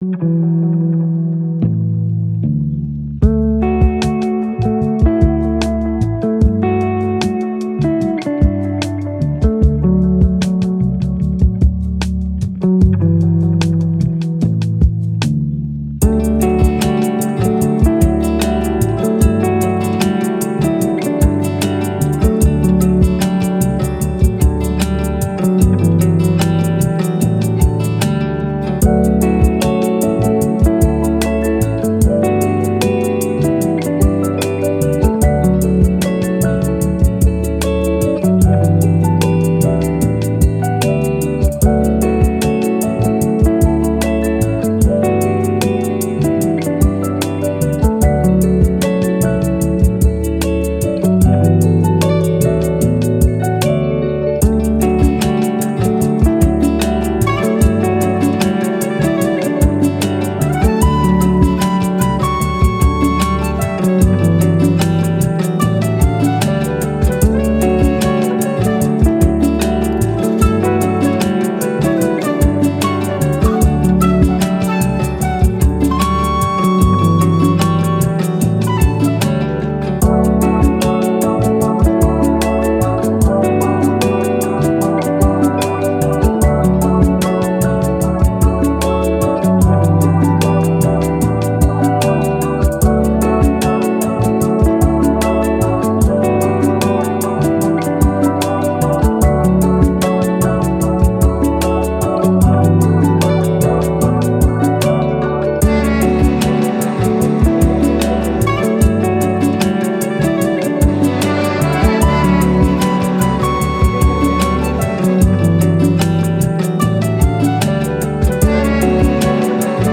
Soul, Lofi, Chill, Hope, Love